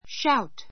ʃáut